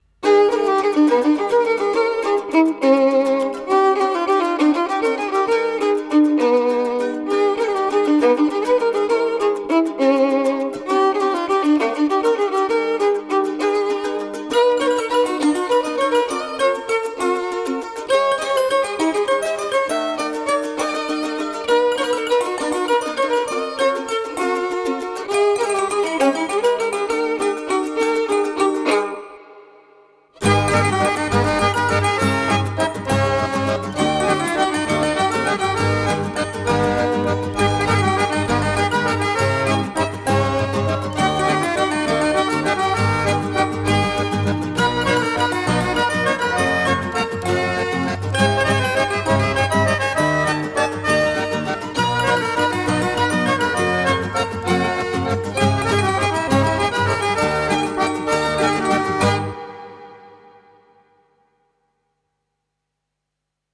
Genre: World Music, Alternative, Neo-Classical, Neo-Folk